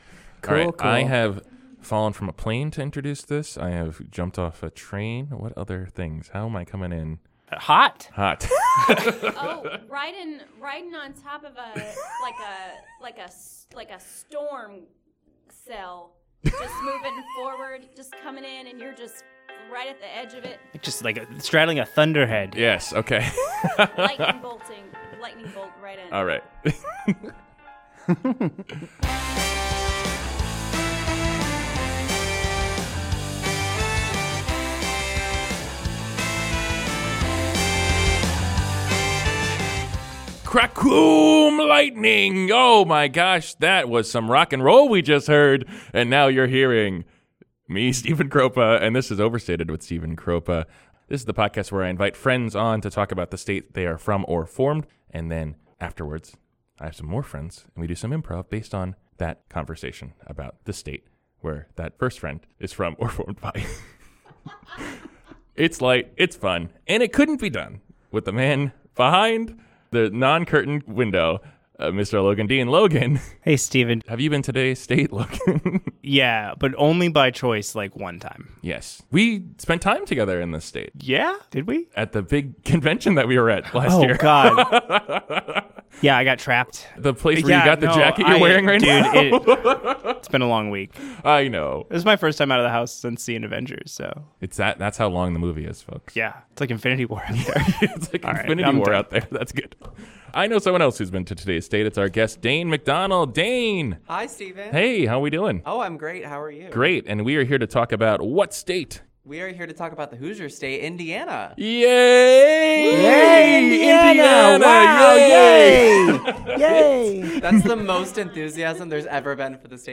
Indiana - A State